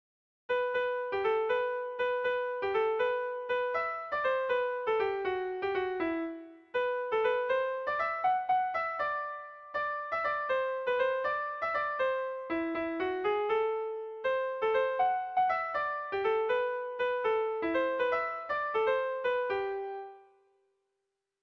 Zazpi puntuko berezia
10A / 11A / 10B / 10B / 5C / 10C / 10C